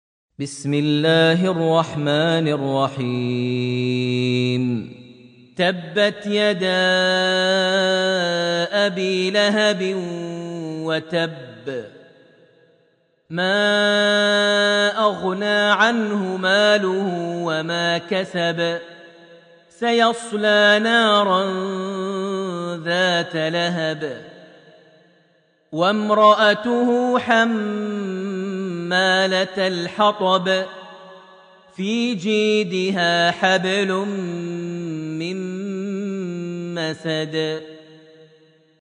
Surat Al-Masd > Almushaf > Mushaf - Maher Almuaiqly Recitations